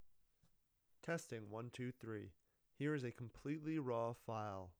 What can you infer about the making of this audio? No normalization or compression.